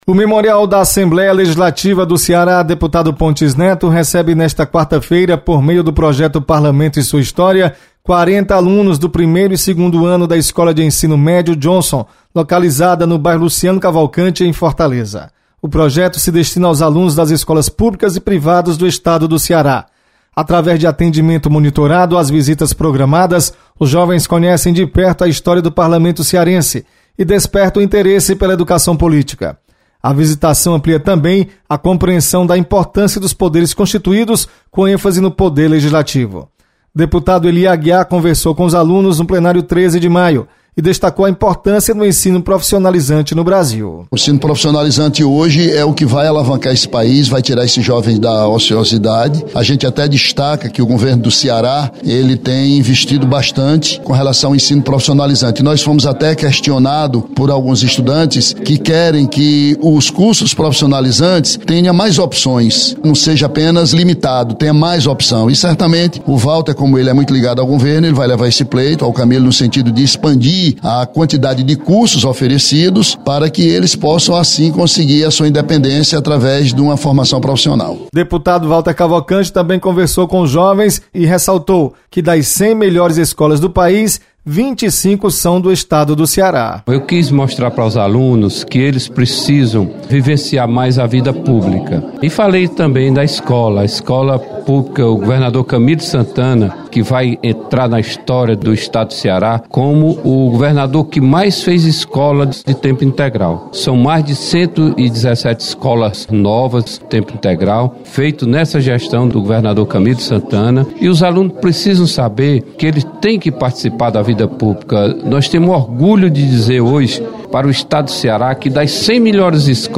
Alunos do Bairro Luciano Cavalcante visitam Memorial Pontes Neto. Repórter